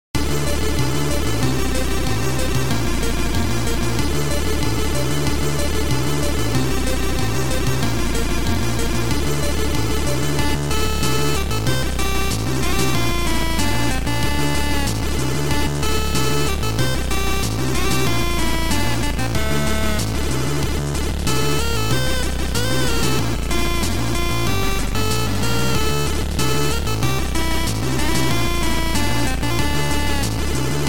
Noisetracker/Protracker